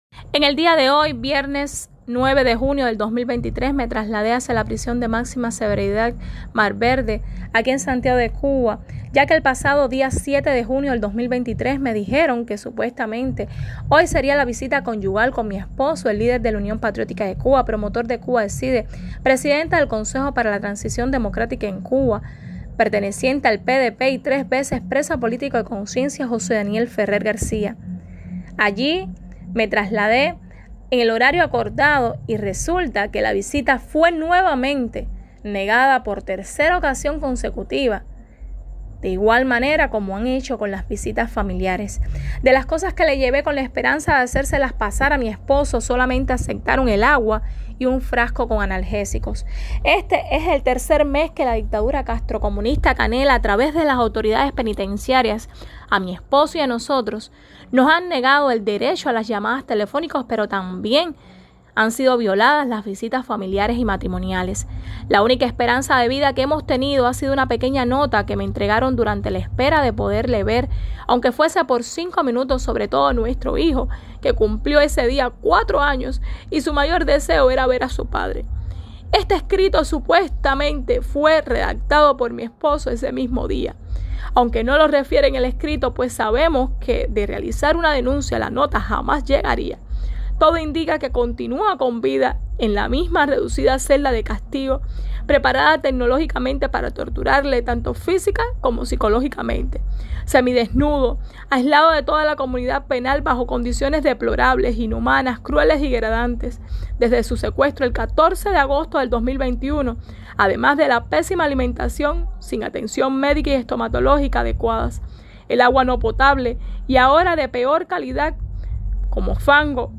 Declaraciones
“No descartamos que este muy mal de salud al pedir en la nota hasta antibióticos de amplio espectro y cuatro tipos de analgésicos cuando mi esposo apenas tomaba medicamentos solo durante crisis”, aclaró en llanto.